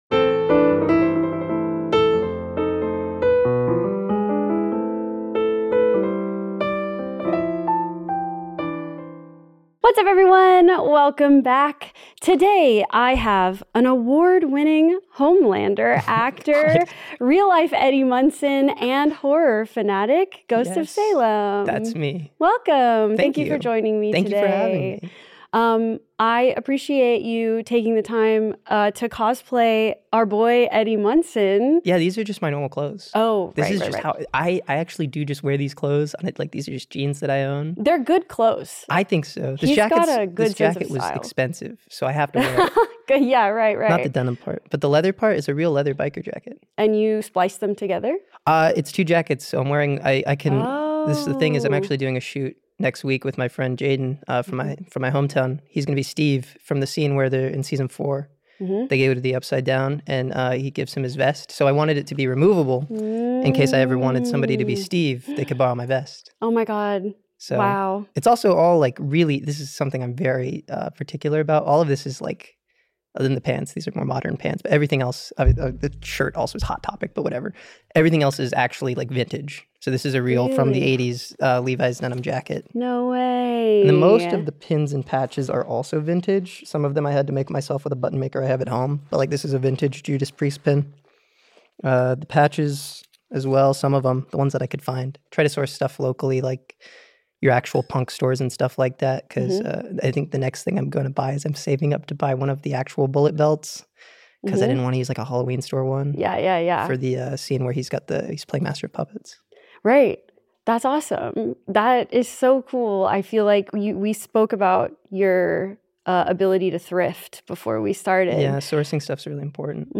It was also heavily raining so you will probably hear that in the background.